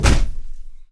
FEETS 3   -R.wav